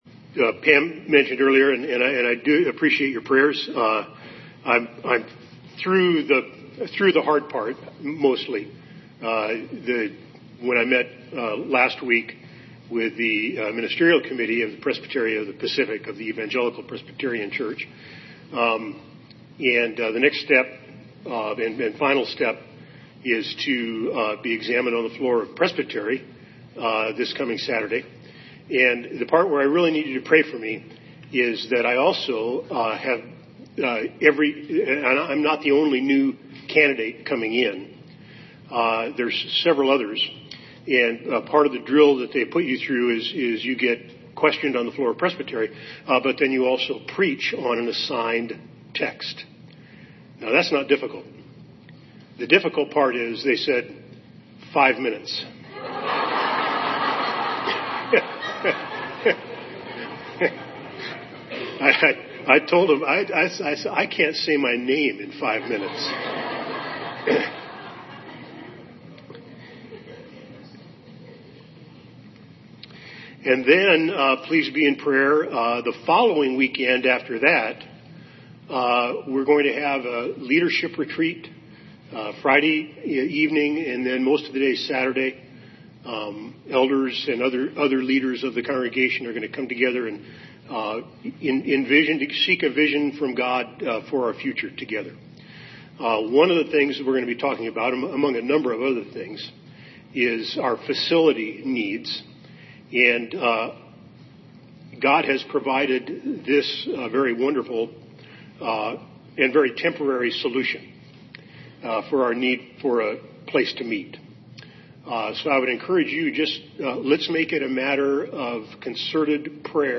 Sermon Series: Essentials of the Faith (Week 1 of 8) Theme: The Authority of the Bible